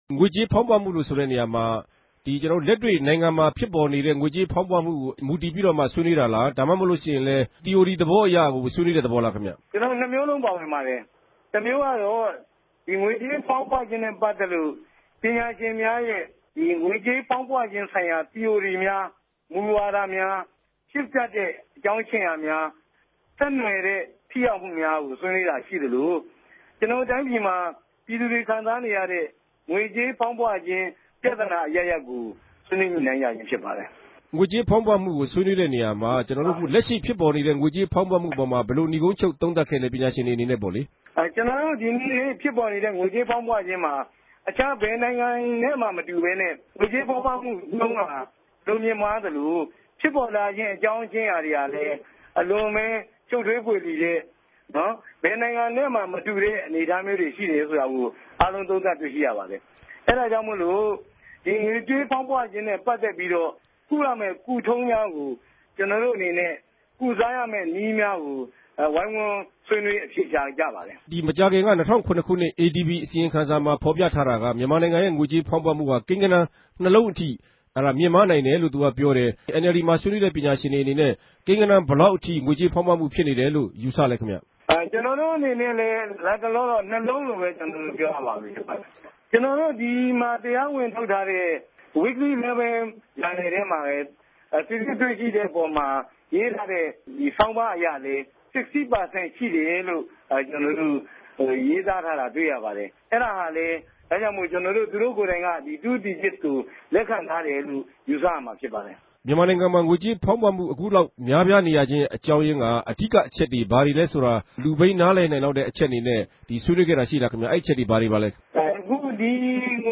ဗန်ကောက် RFA႟ုံးခြဲကနေ ခုလို ဆက်သြယ် မေးူမန်းထားပၝတယ်။